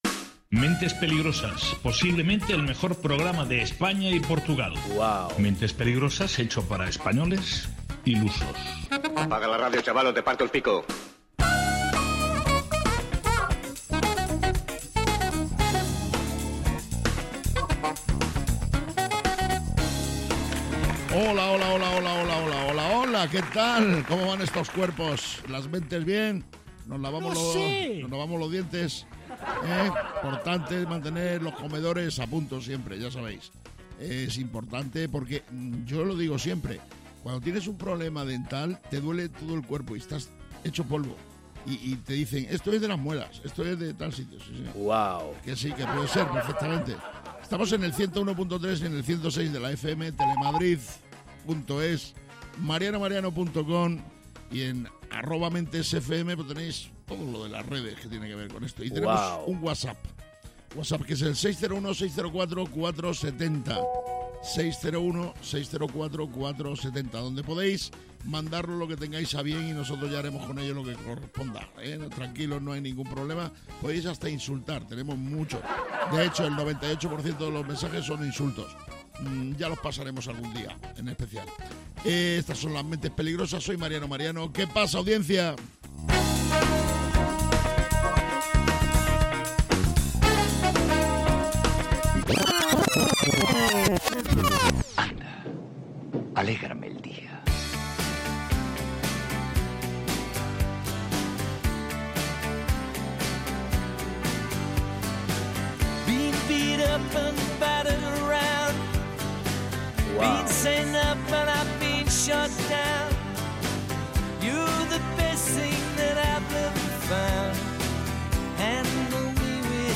Mentes Peligrosas es un programa de radio, esto sí lo tenemos claro, lo que no está tan claro es qué pasará en cada una de sus entregas, no lo saben ni los que lo hacen, ni sus propios entornos. Mentes Peligrosas es humor, y quizás os preguntaréis, ¿y de qué tipo de humor es?